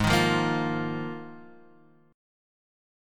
Ebsus2sus4/Ab chord